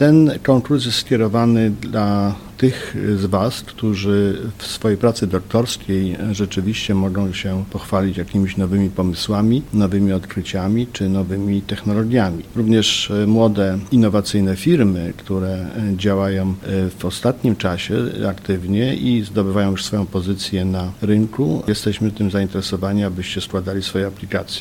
Marszałek województwa mazowieckiego, Adam Struzik mówi, że ten projekt ma na celu wspierać finansowo innowacyjne firmy i kreatywnych naukowców.